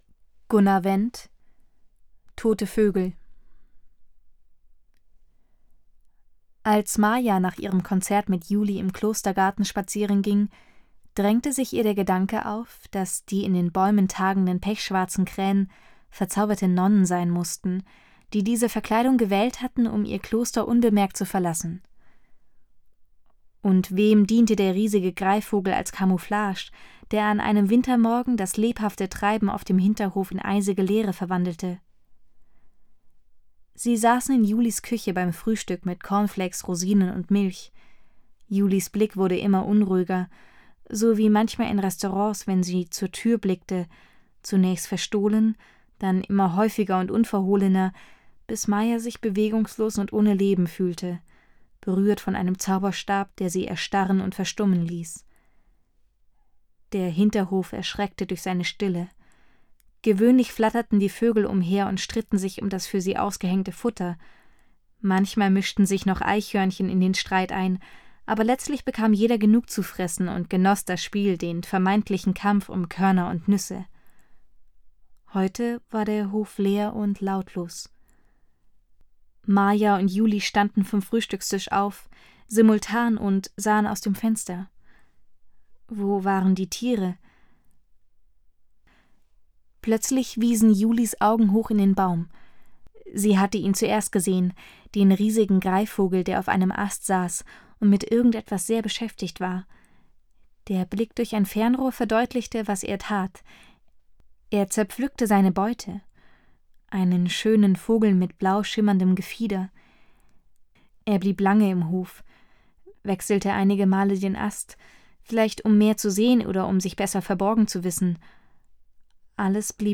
Vortrag einer professionellen Sprecherin [mp3] [10,5 MB] bietet die Möglichkeit zu einer kritischen Bewertung des eigenen Ergebnisses.